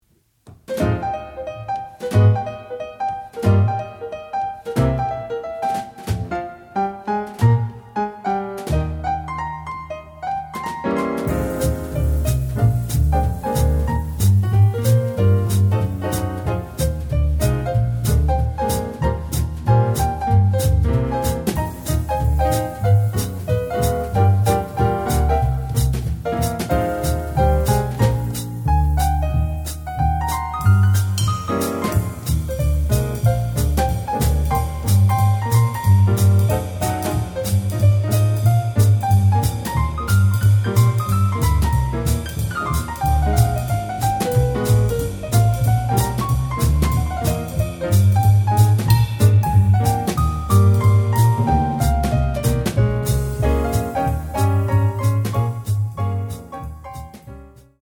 3-piece Jazz Band for Hire
Piano, Double Bass, Drums